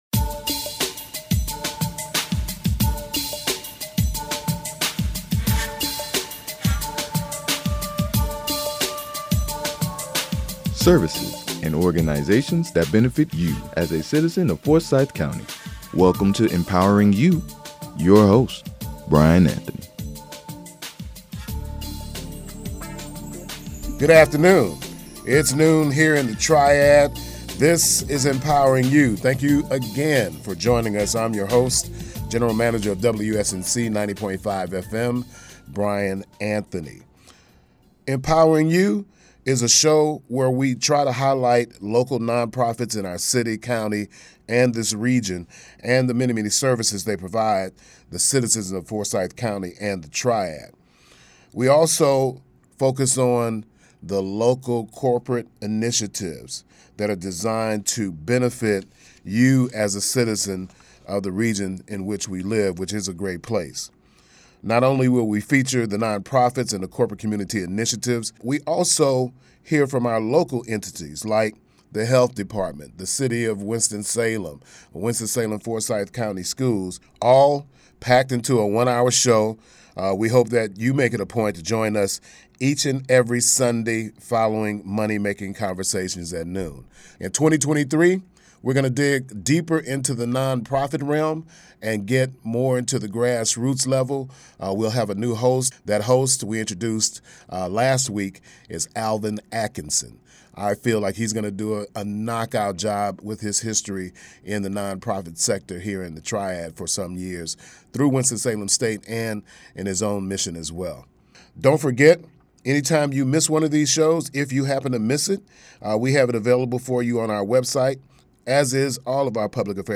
Empowering You is a 1-hour broadcast produced and recorded in the WSNC-FM Studios. The program is designed to highlight Non-Profit organizations, corporate community initiatives that are of benefit to the citizens of our community.